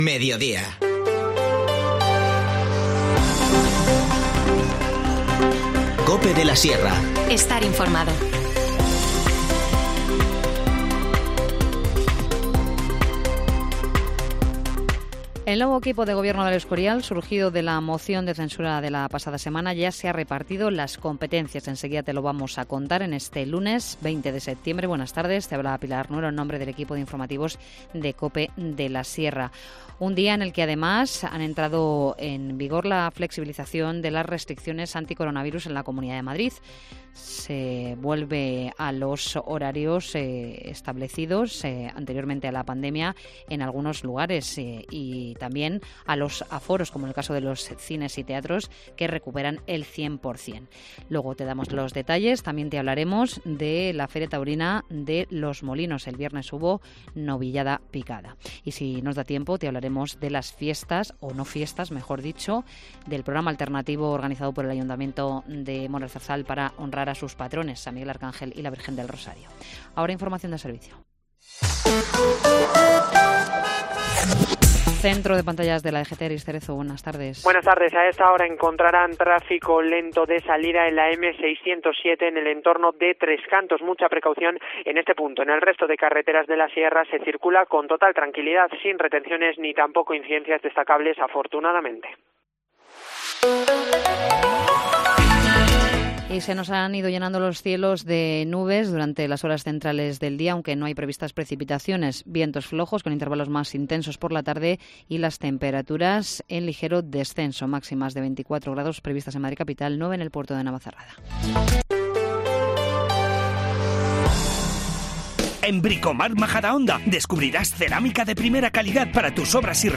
Informativo Mediodía 20 septiembre